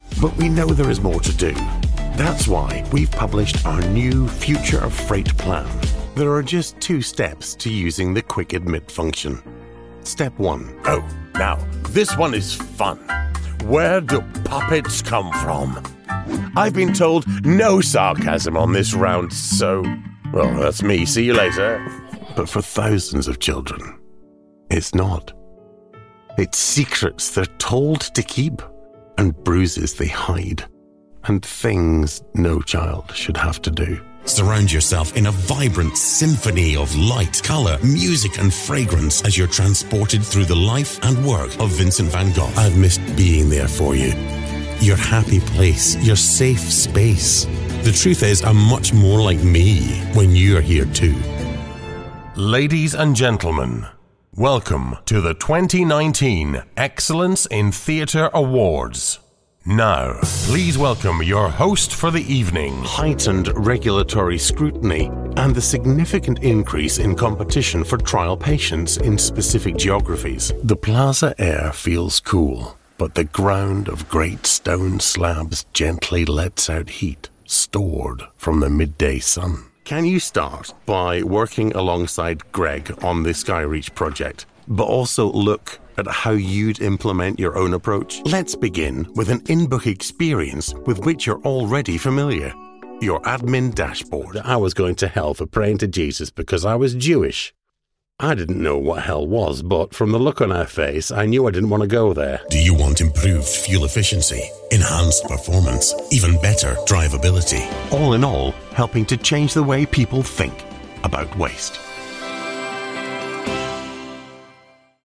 English (British)
English (Scottish)
This gives me a very soft...
Reassuring
Reliable
Fatherly